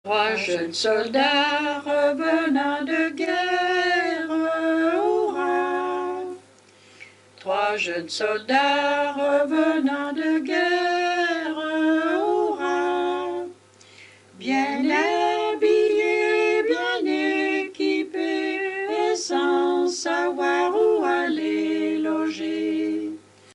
Origine : Haute-Savoie
enregistrées à La Forclaz